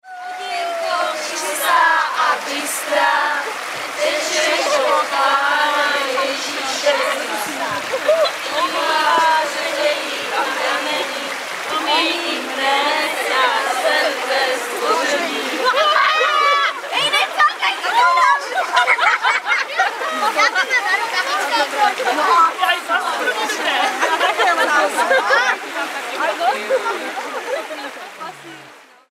Velký pátek 6. dubna 2012, Nivnice ... kolem půlnoci kdesi u říčky Nivničky